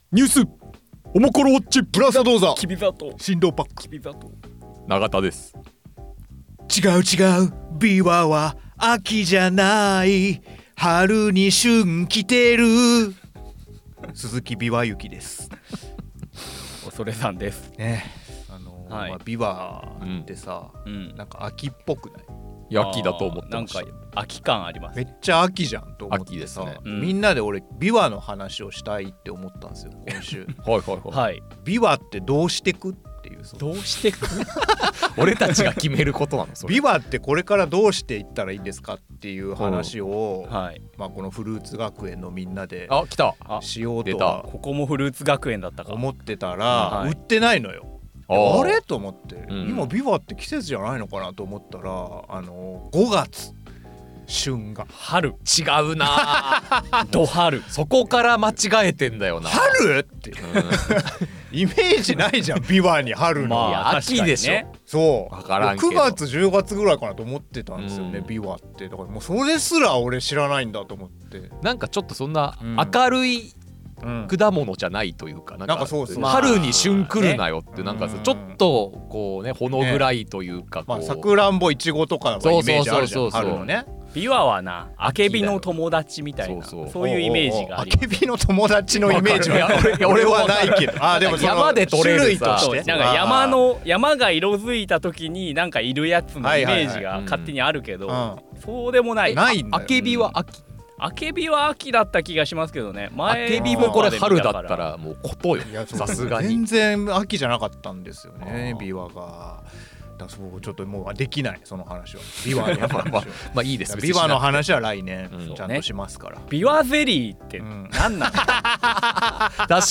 オモコロ編集部の3人が気になるニュースについて語ります。